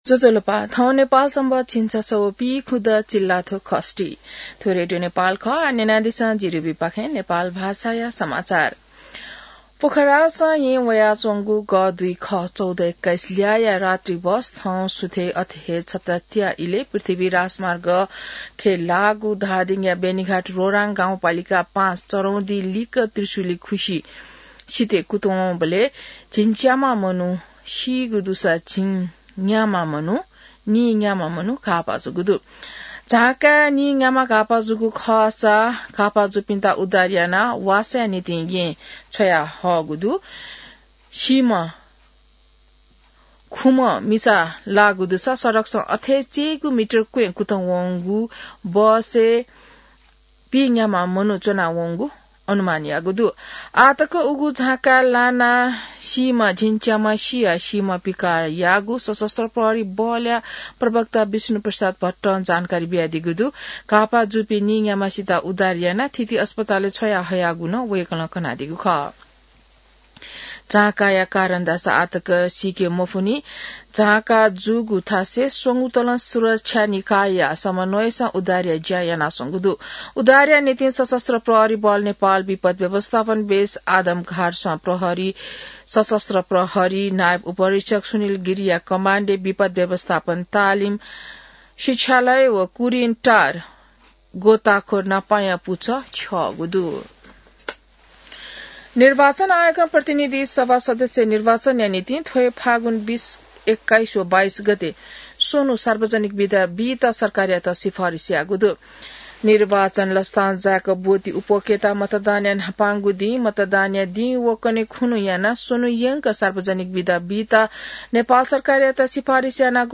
नेपाल भाषामा समाचार : ११ फागुन , २०८२